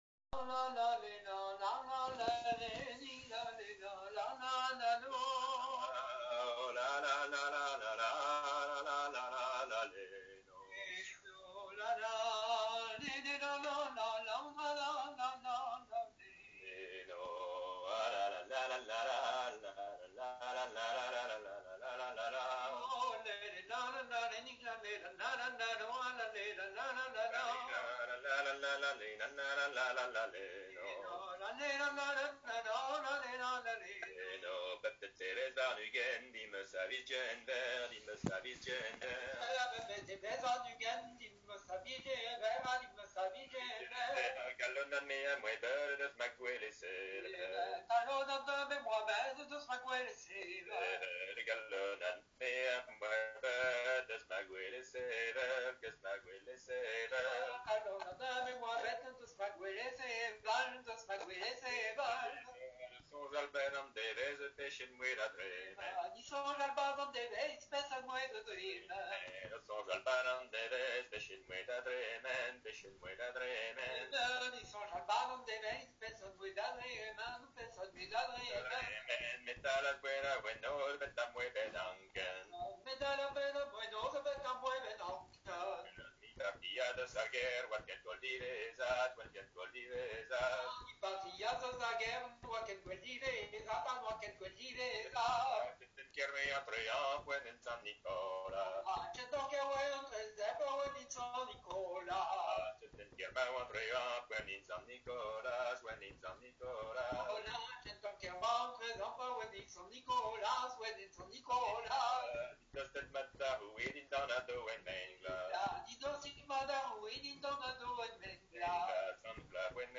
Plinn Ton simple